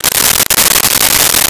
Wrapper Open 01
Wrapper Open 01.wav